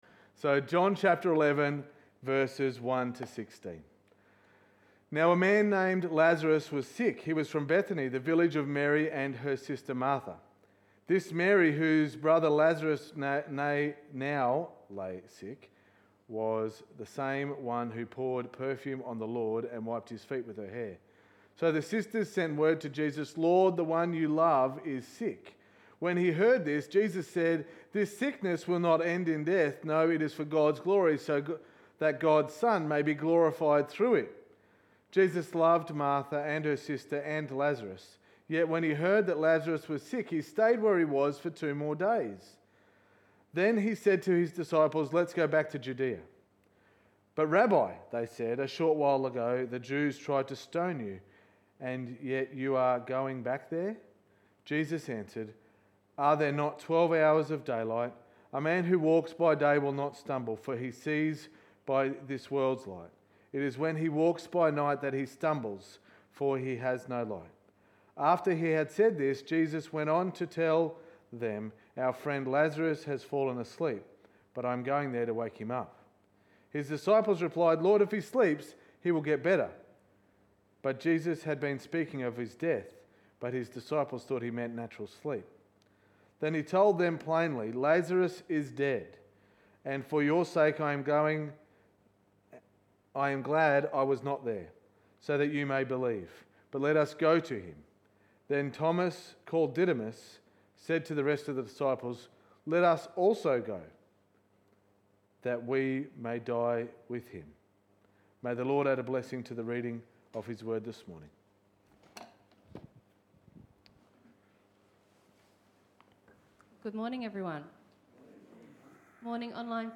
Sermon 12.07.2020